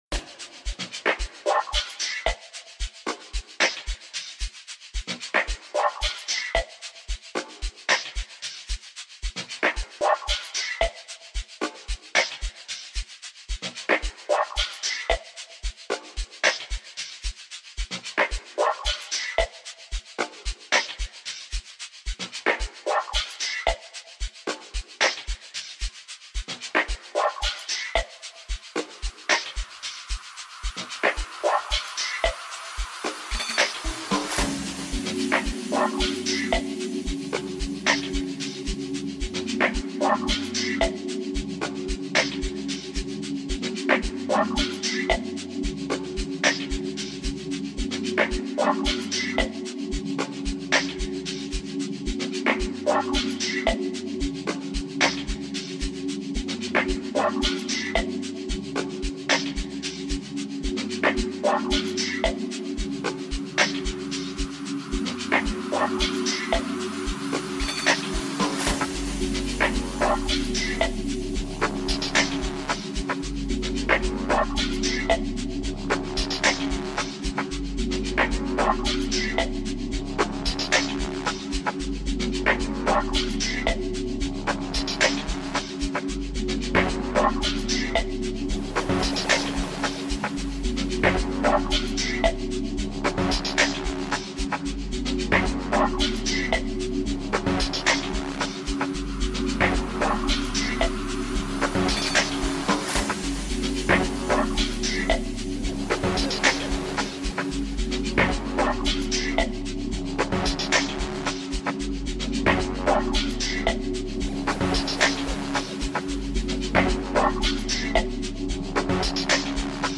features soothing atmospheres and tunes